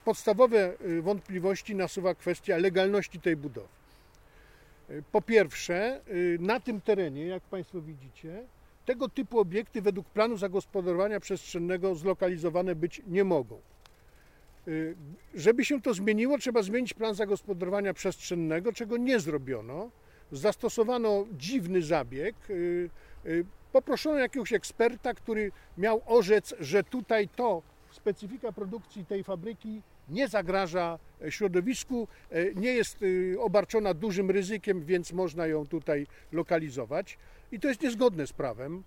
Konferencja Andrzeja Szlęzaka
W Stalowej Woli odbyła się konferencja prasowa radnego sejmiku wojewódzkiego Andrzeja Szlęzaka.